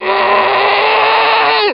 zombie_voice_idle6.mp3